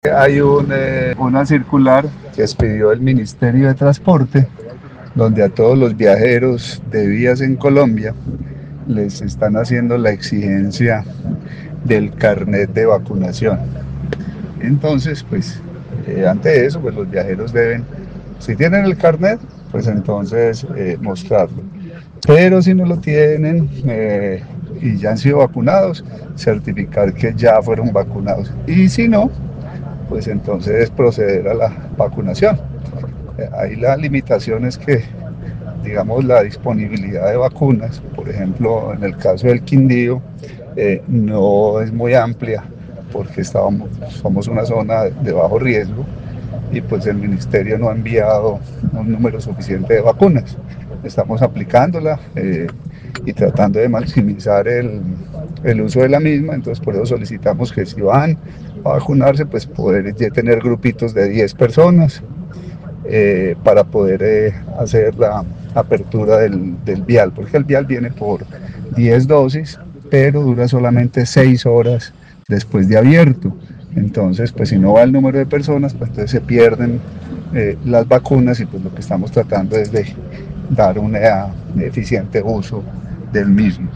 Secretario de Salud del Quindío